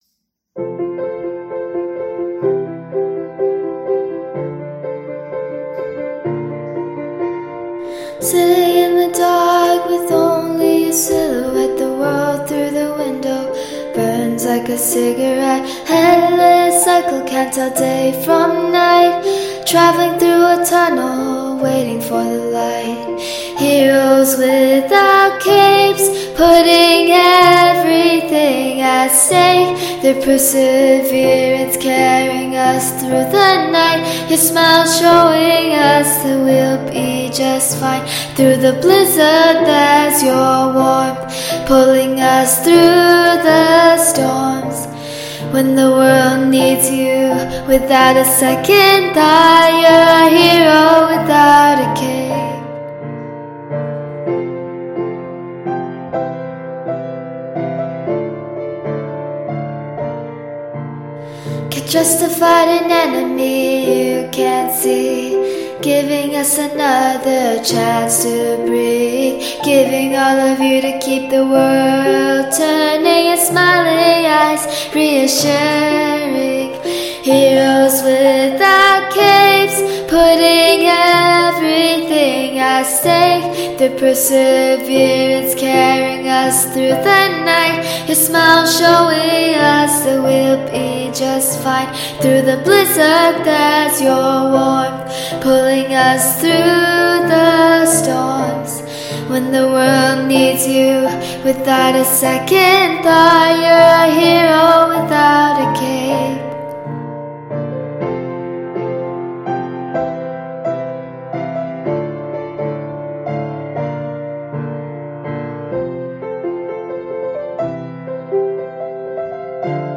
Audio musical
music (performing arts genre)